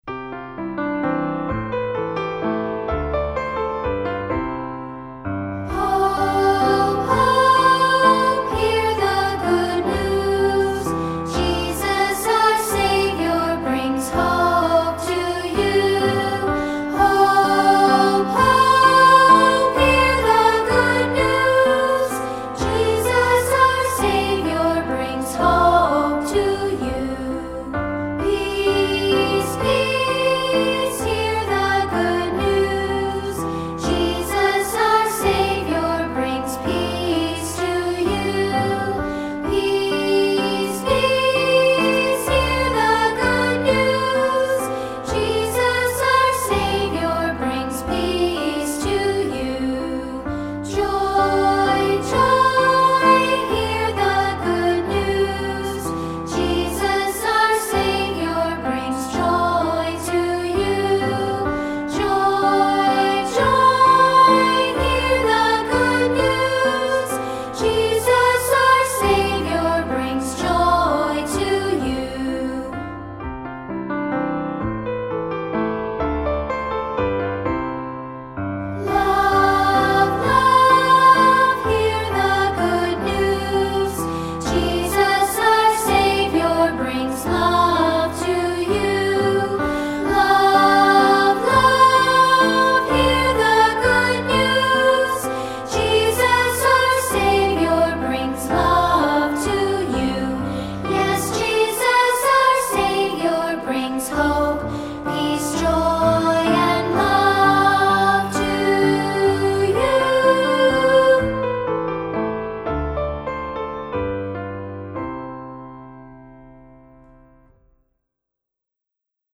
Unison with piano and optional congregation